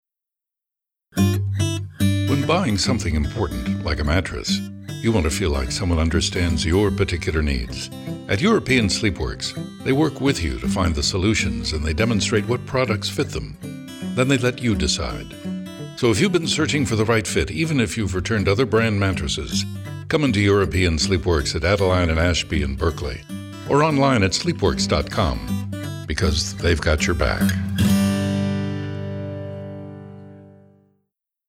• Campaign utilizes key sonic identifiers including the unique and recognizable voice, paired with a consistent music style for its radio commercials that have led to lifts in brand awareness, traffic and sales.